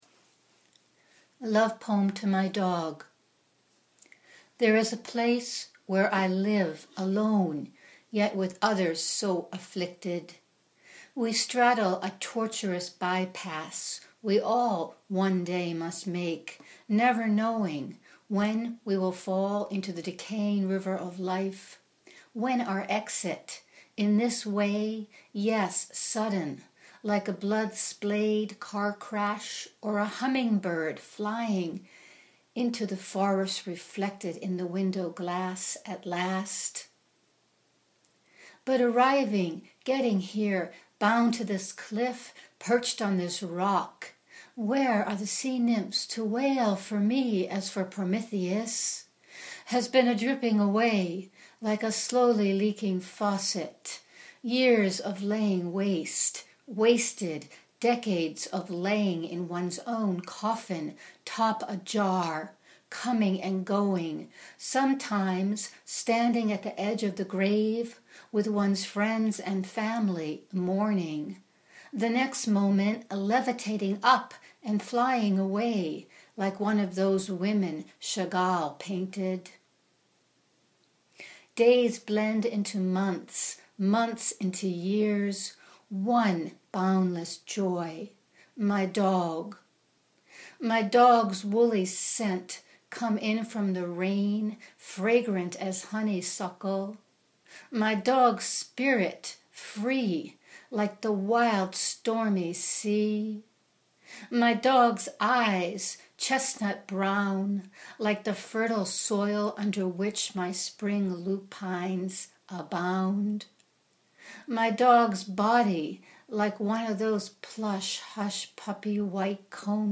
(Click above for an audio recording of my reading this poem.)